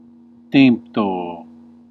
Ääntäminen
US : IPA : [ɪn.ˈsɑɪt]